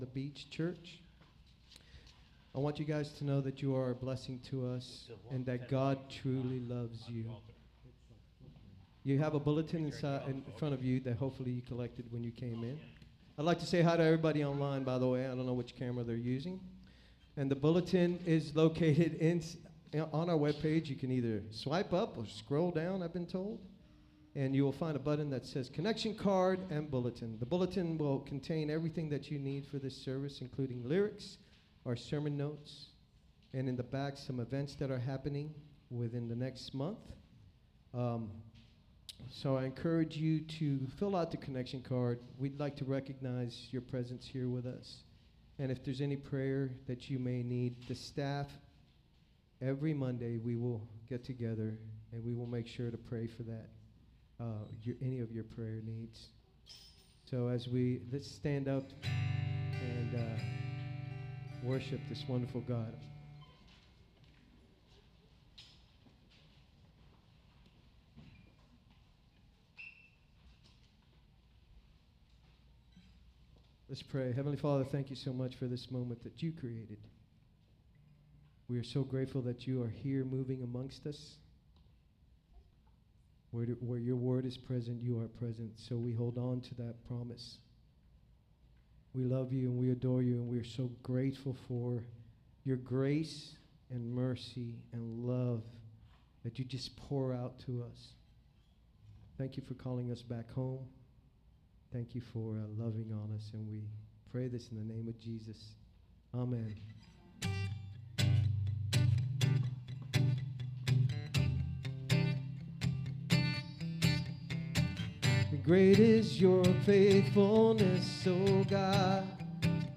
SERMON DESCRIPTION Joseph revealed himself to his brothers with tears, showing how guilt can fill us with fear.